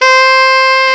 Index of /90_sSampleCDs/Roland LCDP07 Super Sax/SAX_Alto Short/SAX_A.ff 414 Sh
SAX A.FF C0F.wav